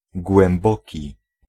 Ääntäminen
Synonyymit songé long anus Ääntäminen Tuntematon aksentti: IPA: /pʁɔ.fɔ̃/ Haettu sana löytyi näillä lähdekielillä: ranska Käännös Ääninäyte Adjektiivit 1. głęboki {m} Suku: m .